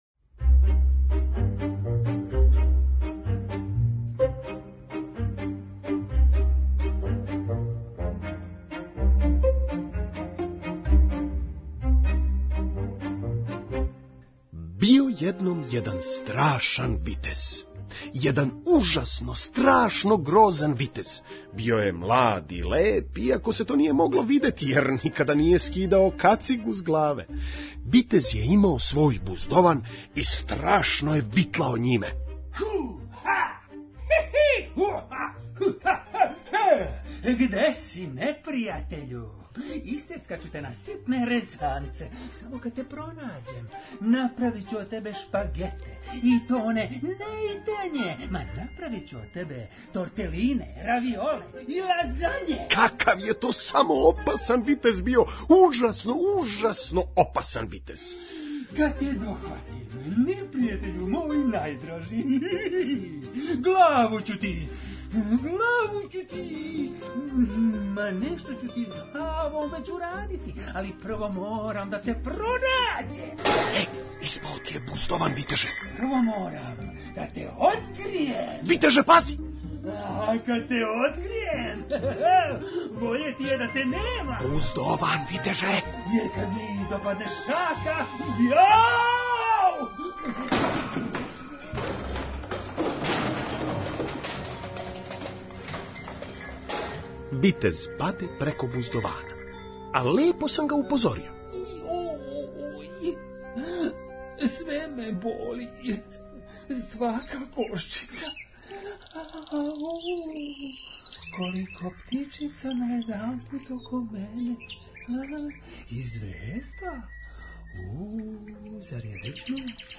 Драмски програм за децу